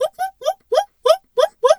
zebra_whinny_11.wav